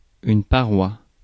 Ääntäminen
France: IPA: /pa.ʁwa/